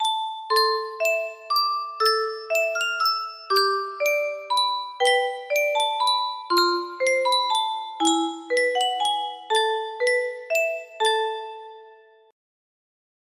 Green Sleeves_practice ver. music box melody
Grand Illusions 30 (F scale)